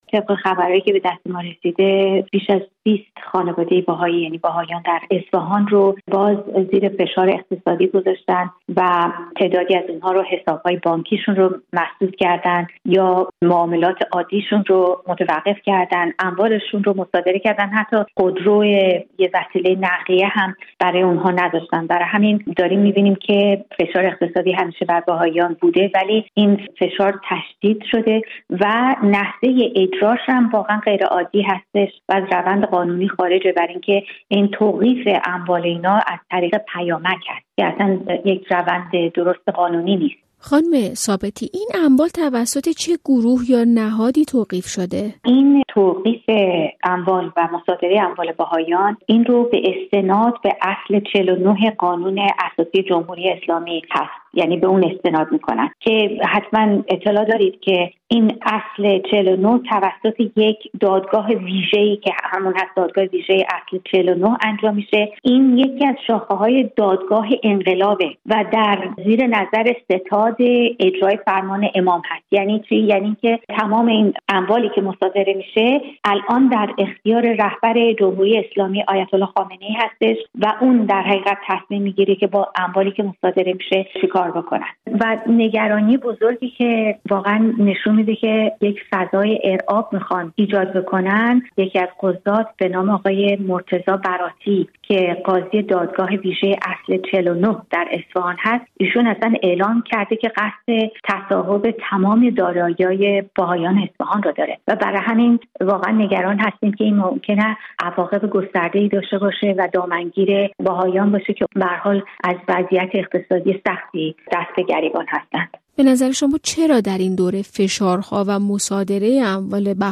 مصادرۀ اموال بیش از ۲۰ بهائی در اصفهان در گفت‌وگو